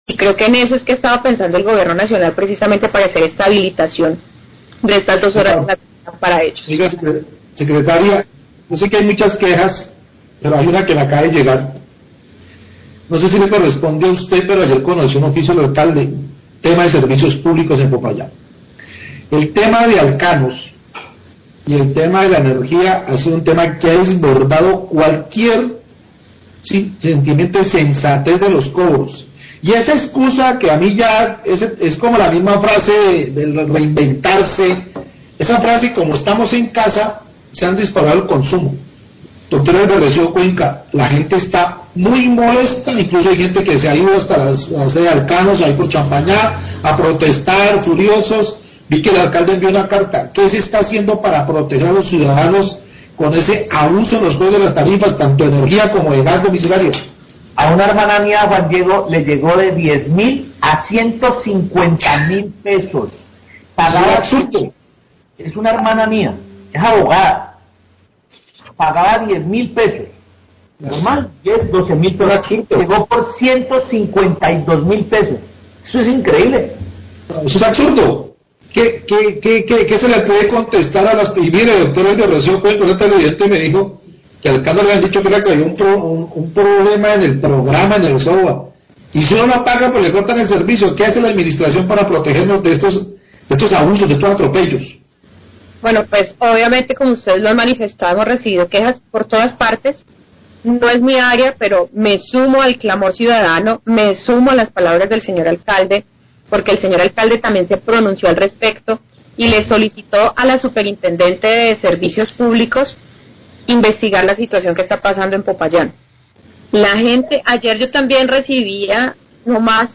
Sria Gobierno Popayán habla de carta alcalde a Superservicios,
Radio